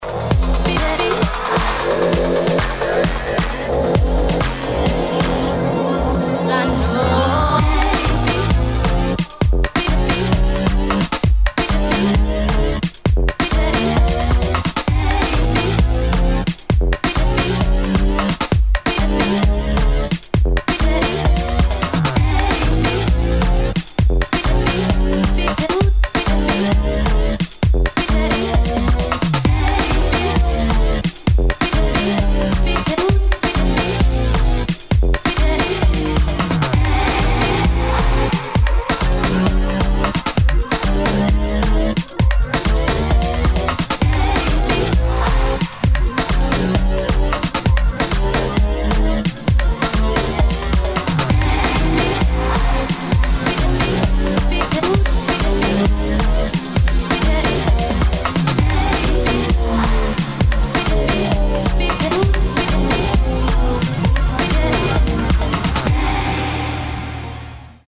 2. DUBSTEP | BASS
[ BASS ]